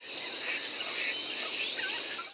Swamp Sparrow diurnal
Swamp Sparrow diurnal flight calls